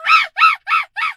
monkey_2_scream_06.wav